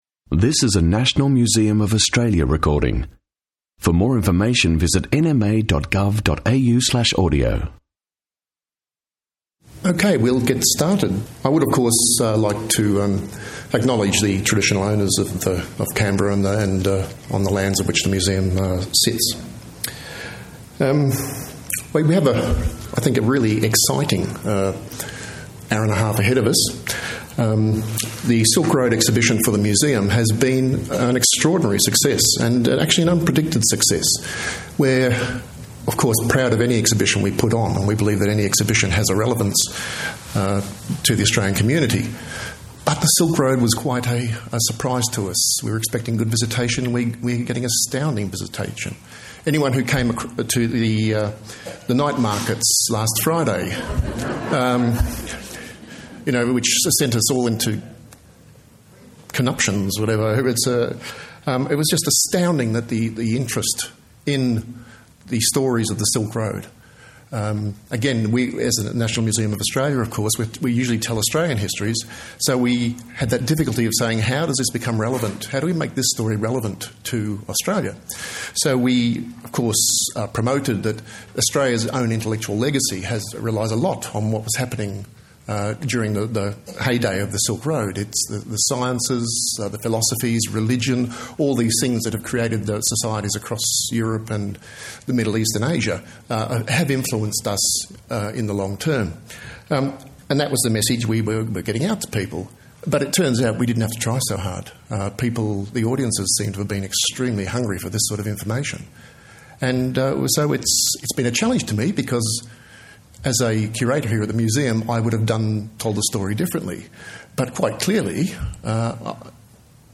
Travelling the Silk Road: public conversation | National Museum of Australia